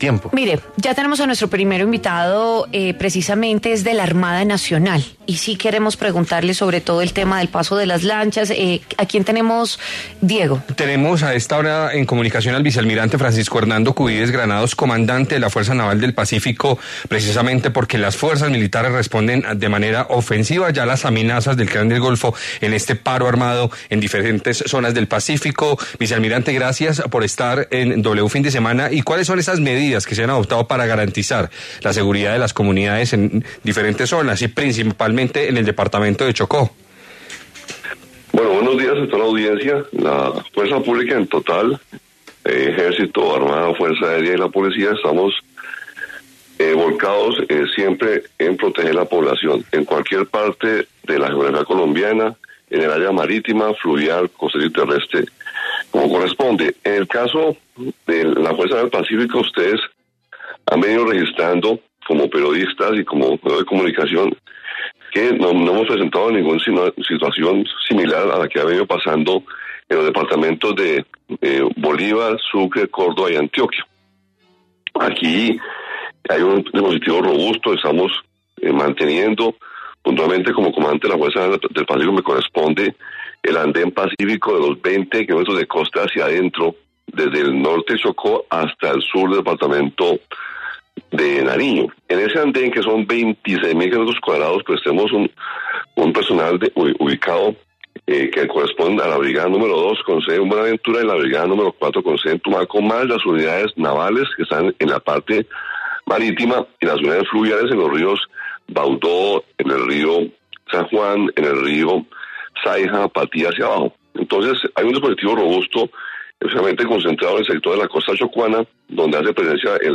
En W Fin de Semana, el vicealmirante Francisco Hernando Cubides se pronunció sobre las acciones perpetradas por el Clan del Golfo tras la extradición de alias ‘Otoniel’.